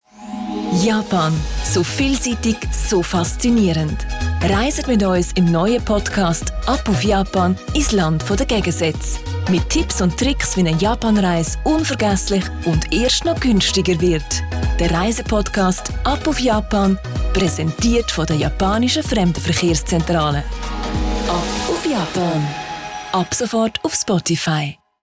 Werbung Schweizerdeutsch (AG)
Sprecherin mit breitem Einsatzspektrum.